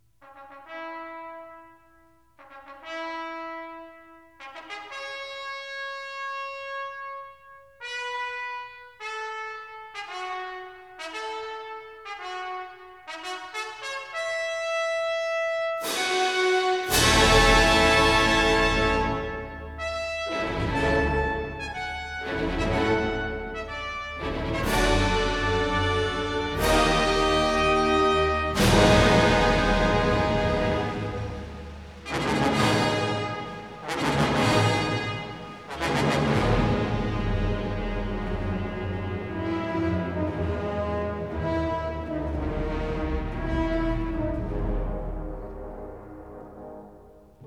C sharp minor